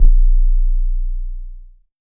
BASS 9.wav